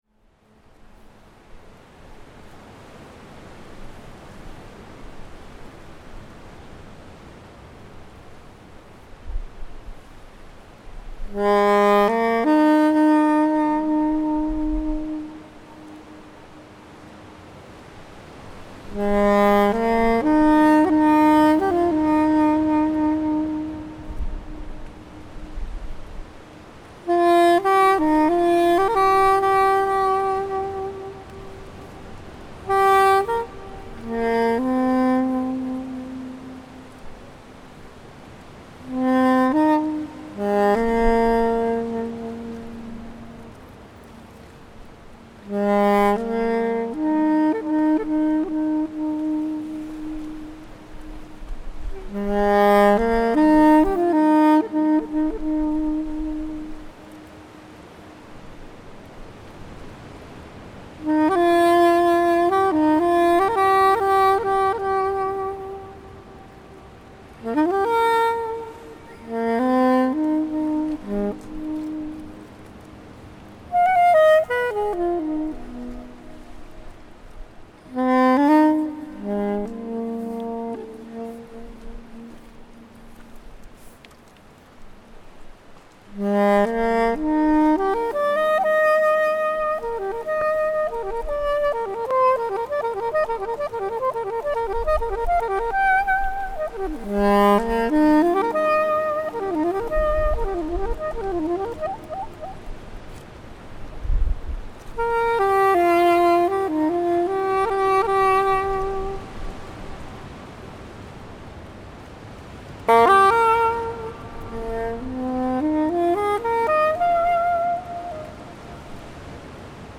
Improvisation